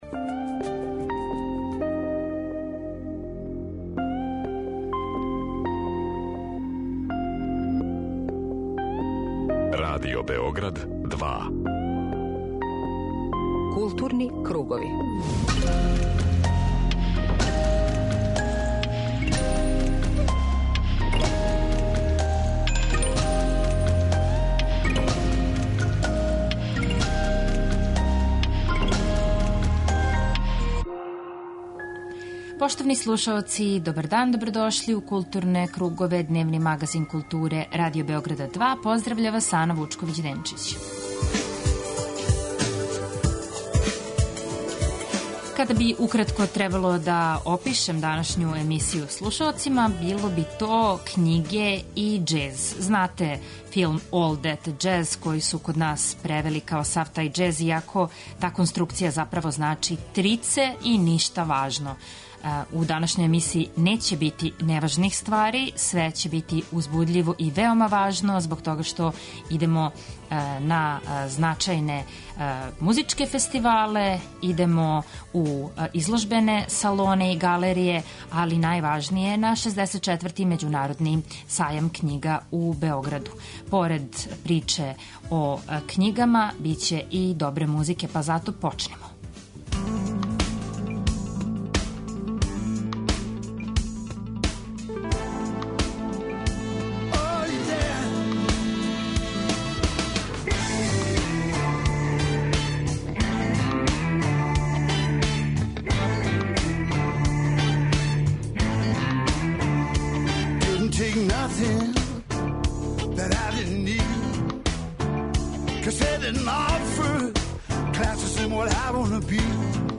Магазин културе Радио Београда 2
Синоћ је ова изложба под називом Интуитивност са маргине отворена у Галерији РТС-а, па ћемо чути звучну забелешку са овог догађаја.